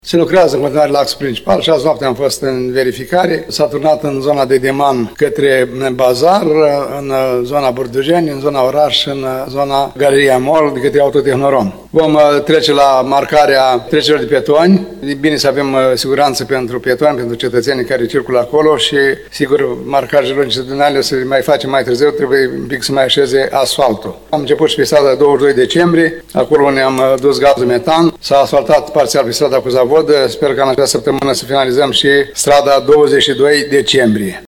În conferința de presă de ieri, primarul ION LUNGU a detaliat principalele lucrări de refacere a carosabilului aflate în desfășurare.